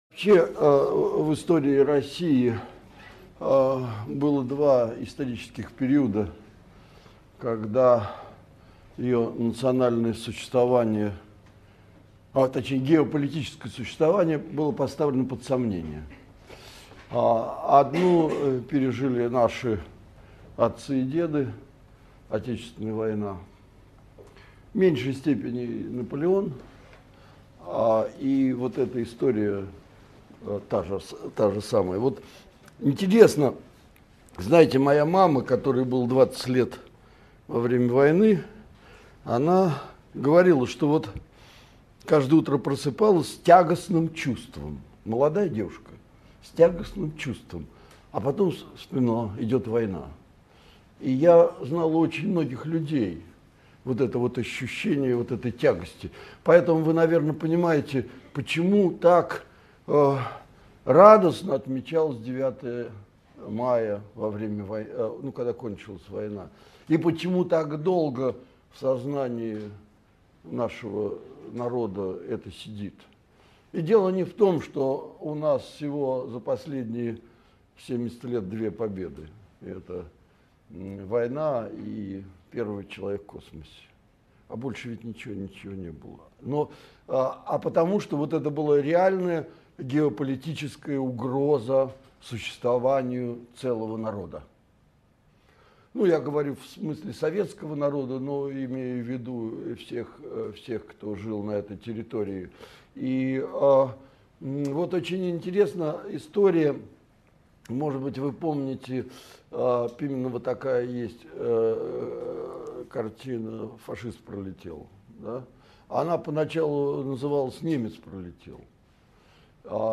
Аудиокнига Монголы. Падение Руси | Библиотека аудиокниг
Прослушать и бесплатно скачать фрагмент аудиокниги